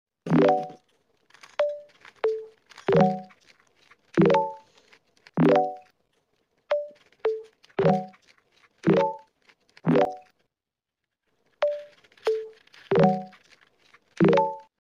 Most Satisfying Rubik's Cube Solves sound effects free download